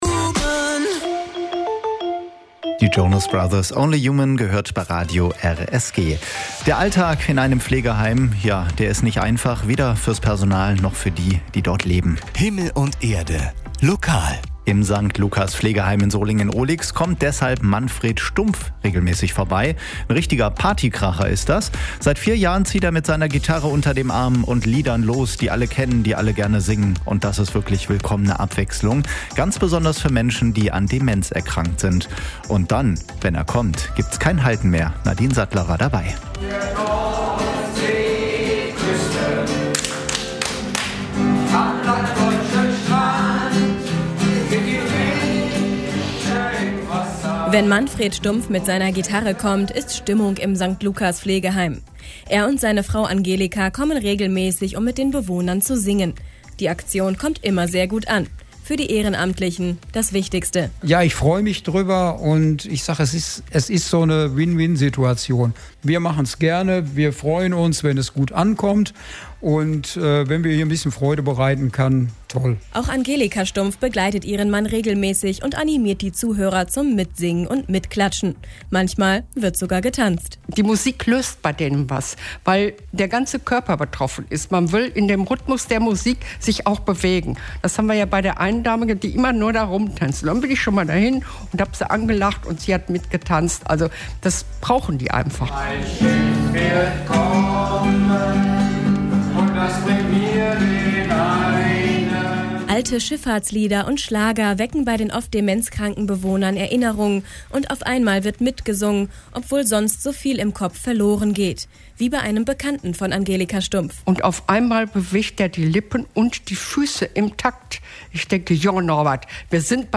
januar_2020_-_musik_im__pflegeheim_.mp3